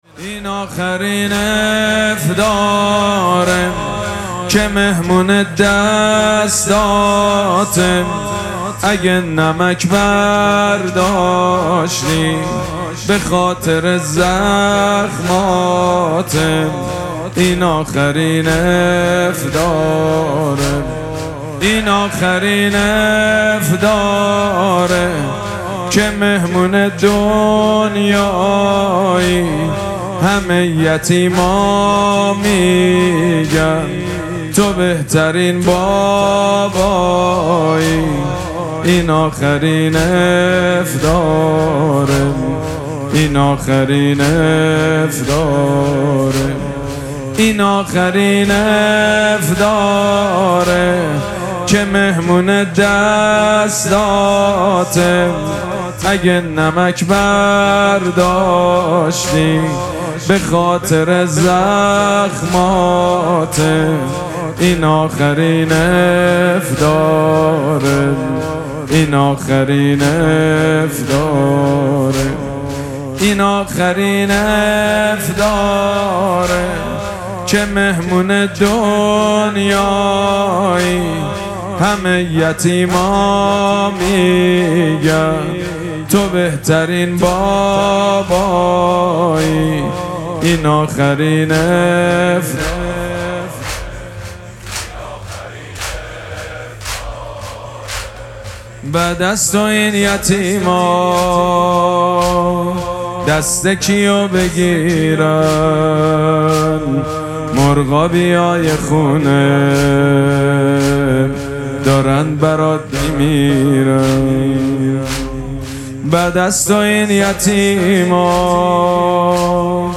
مراسم مناجات شب نوزدهم ماه مبارک رمضان
حسینیه ریحانه الحسین سلام الله علیها